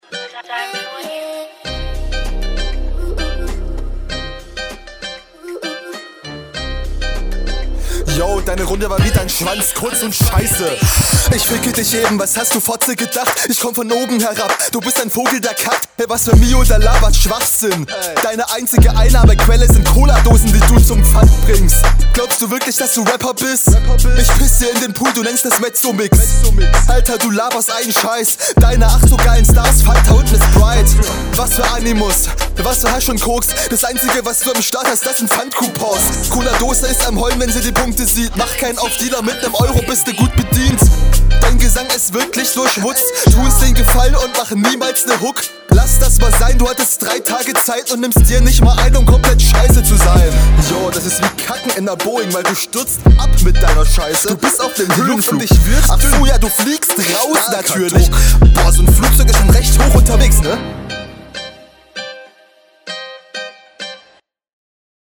Flow kommt auch chillig Du hast dich einfach ein bisschen lauter gemixt als der Gegner. …